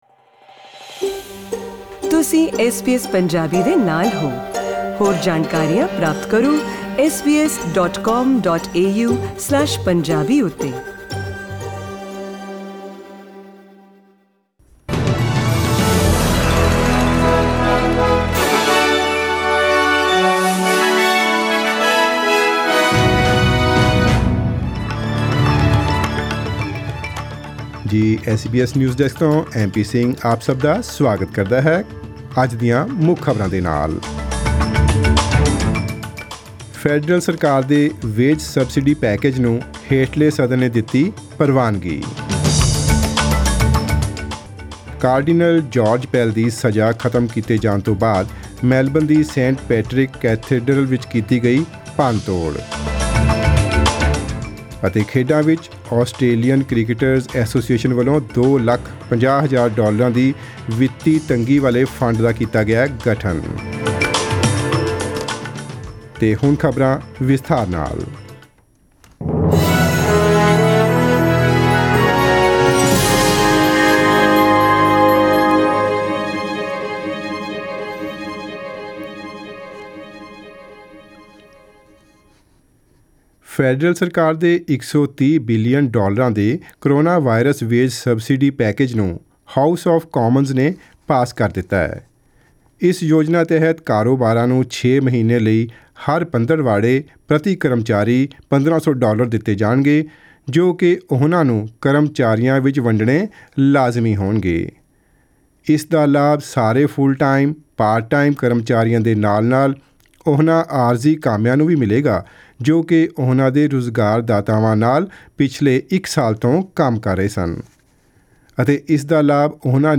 In today’s news bulletin: The federal government's wage subsidy package passes the lower house, Melbourne's St Patrick's Cathedral vandalised after Cardinal George Pell's convictions were quashed; and, In sport, the Australian Cricketers' Association launches a $250,000 financial hardship fund.